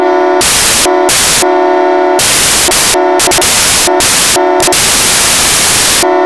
This Section For Non UK Horns
841_HORN.wav